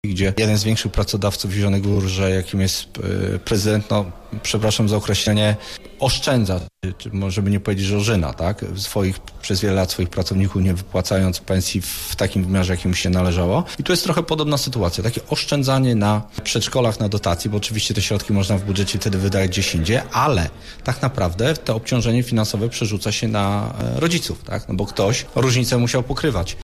Jak dodał gość Rozmowy Punkt 9.00 sytuacja jest podobna do sprawy wynagradzania pracowników miejskich.